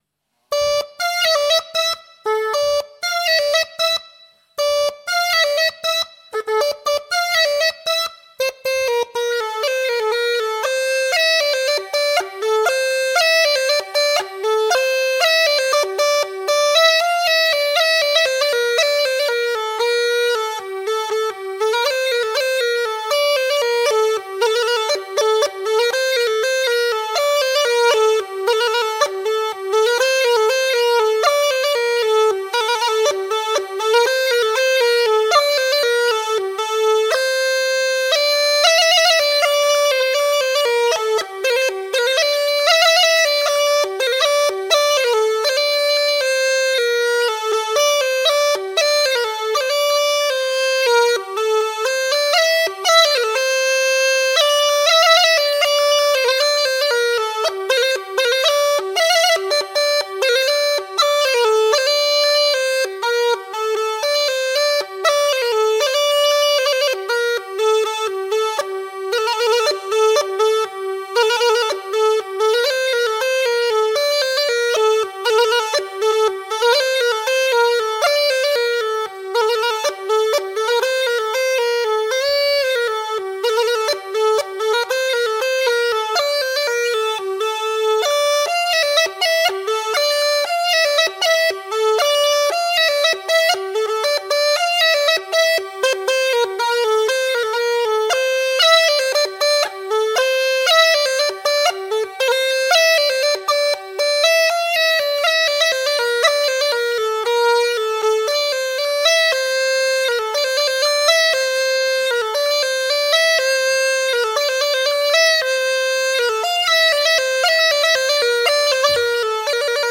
تمپو ۱۱۸ دانلود